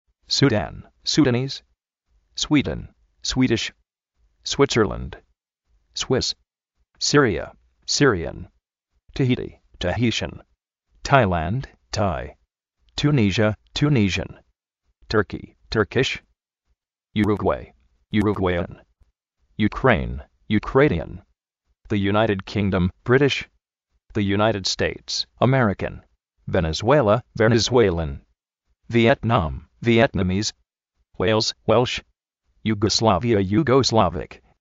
pákistan, pakistáni
pánama, panaménian
perú, perúvian
pórchugal, porchuguí:s
rásha, ráshan
skótland, skótish
spéin, spánish, spániard